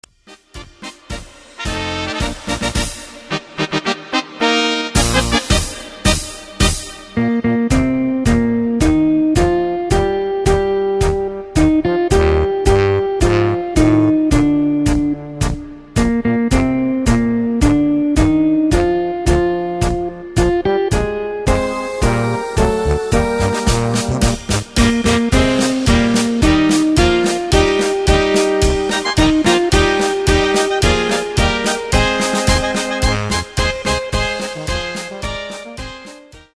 Takt:          2/4
Tempo:         109.00
Tonart:            Bb
Polka Blasmusik!